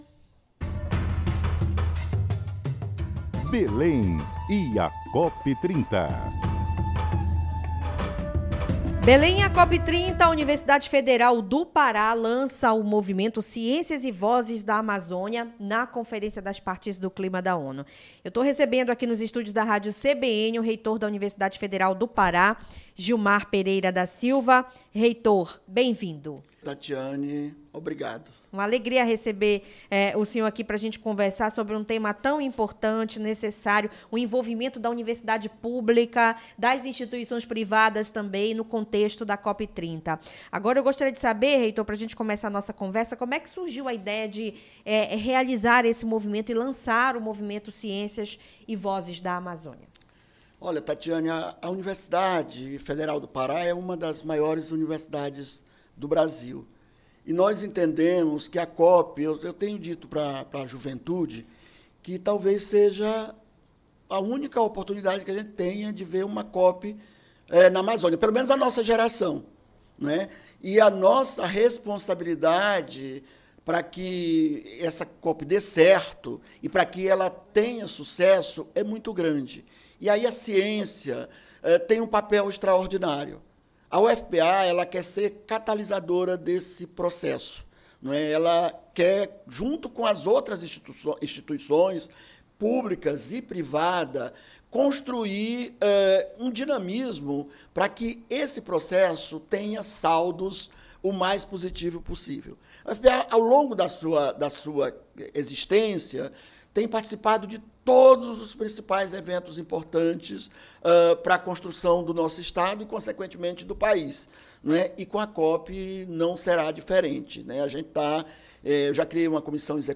Acompanhe entrevista do Professor Doutor Gilmar Pereira da Silva, Reitor da UFPA, na CBN Amazônia Belém.
ENTREVISTA-REITOR-UFPA.mp3